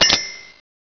Bell.wav